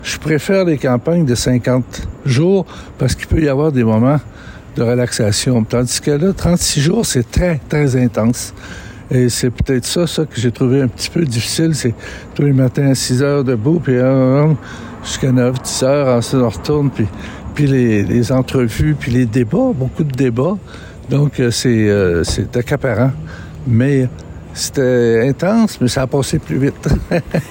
En entrevue, celui qui en était à ses 13e élections depuis 1984 a expliqué pourquoi il a trouvé celle-ci particulièrement exigeante.